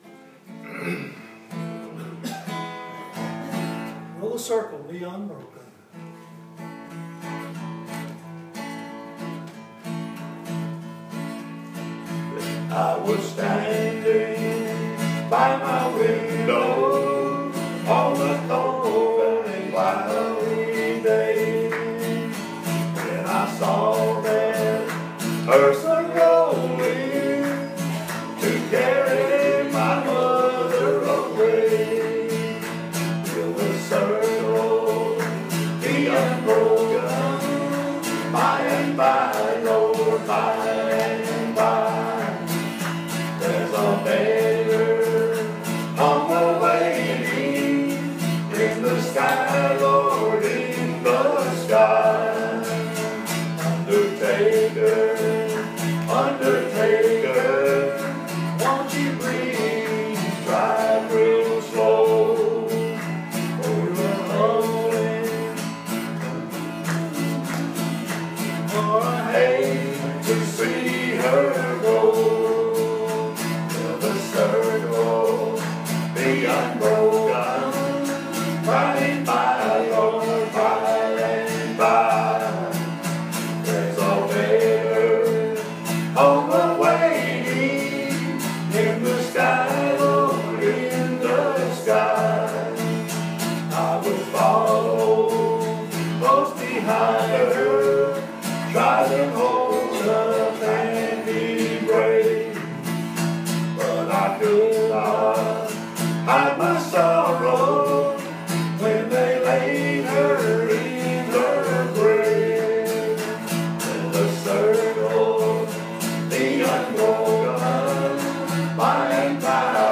An audio presentation of our Sunday, July 2nd Study at Riverview Baptist Church, 798 Santa Fe Pike, Columbia, TN 38401